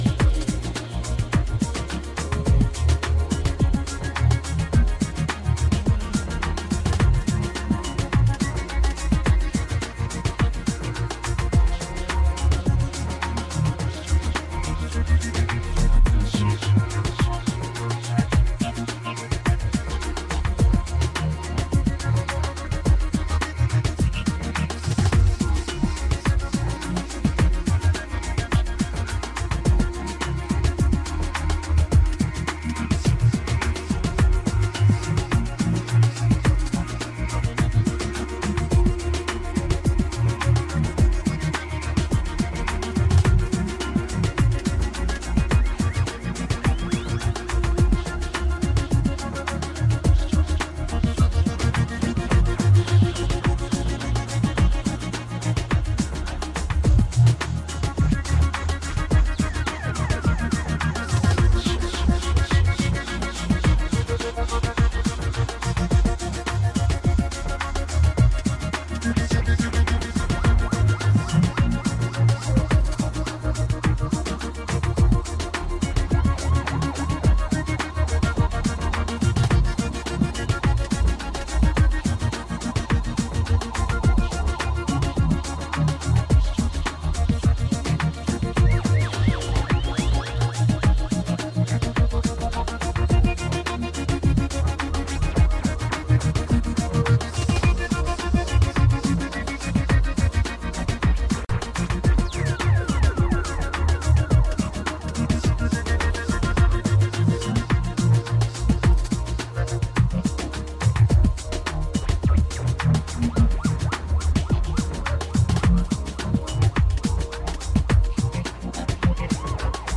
やはり今回も90’s IDMの精神性を感じさせる美的センスが横溢。